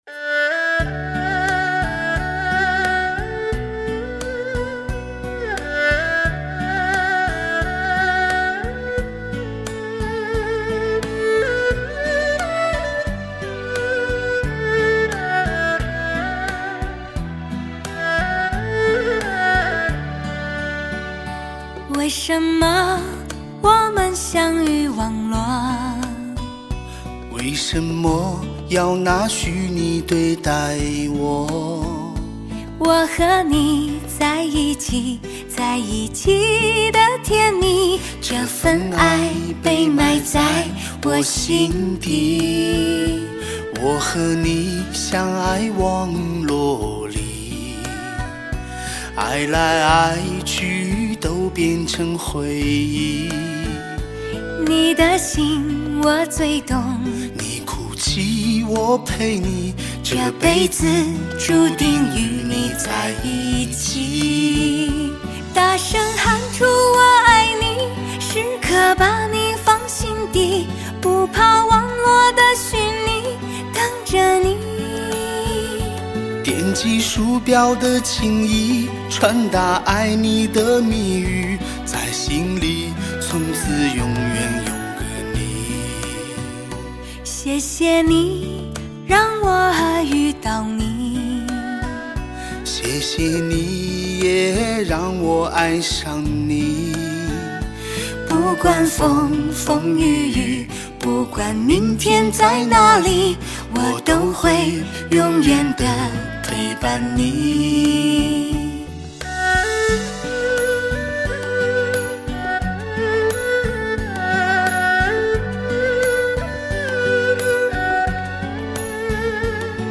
钻石级靓声天碟，情歌精选集。
15首对唱情歌演绎男女间的情感世界--“体验真爱感动满载，有情天地，逐首细味！